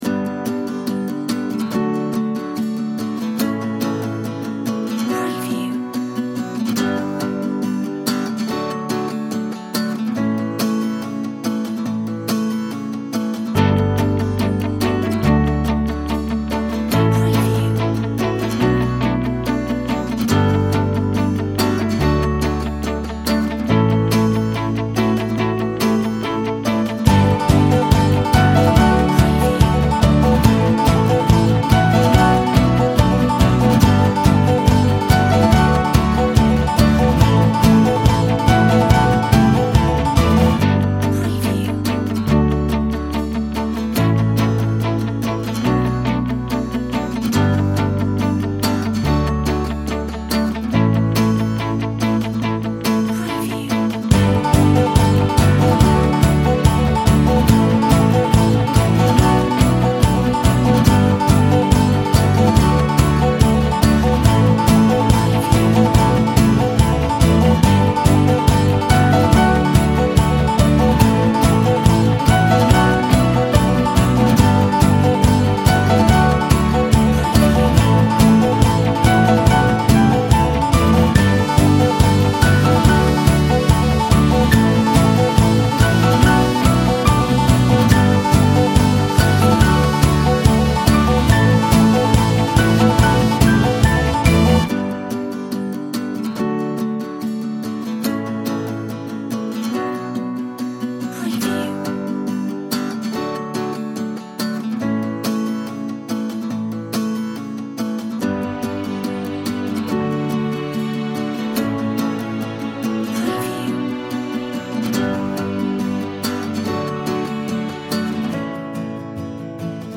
Inspiring acoustic